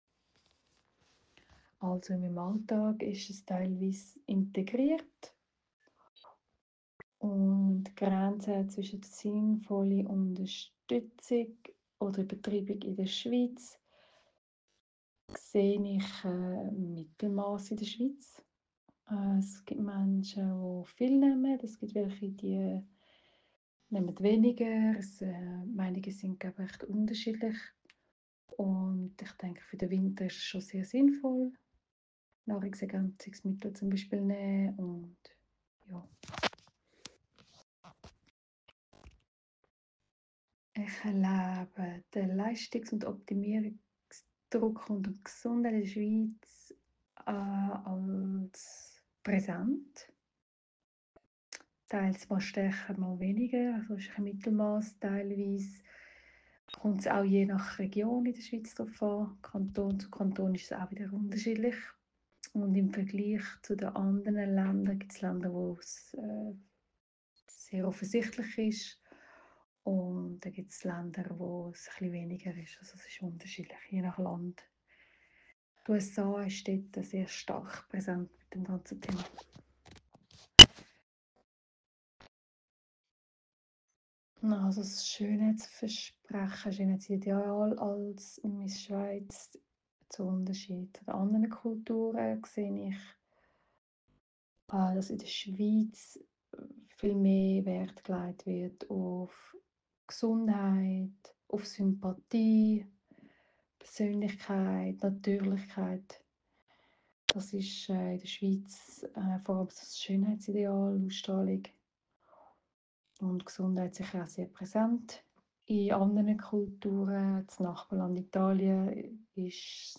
Interview Recording in Swiss German (original)